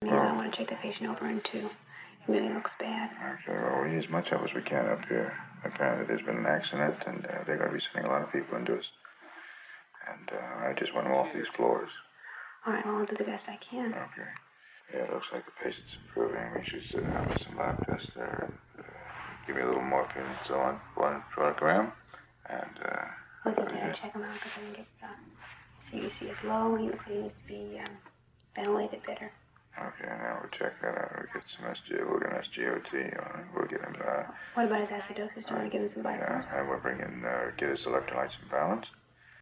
Patient Discussion
SFX
yt_2DNIcBQdeQs_patient_discussion.mp3